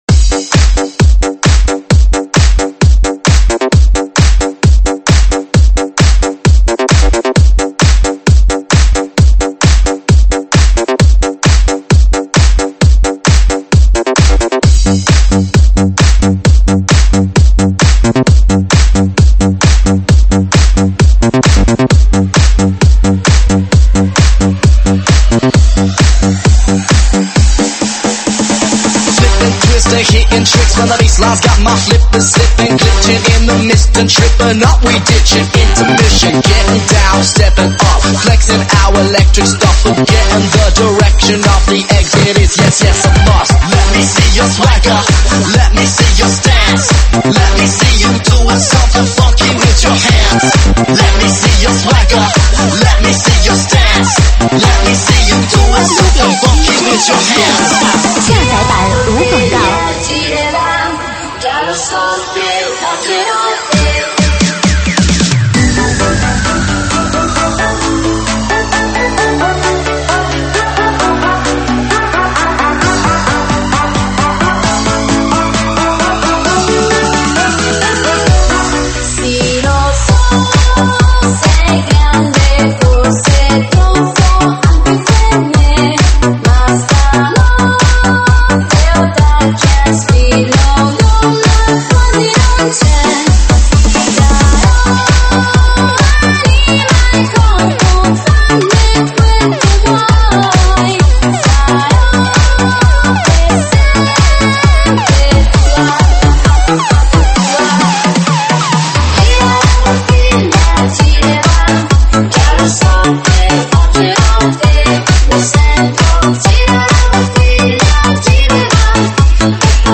舞曲类别：英文舞曲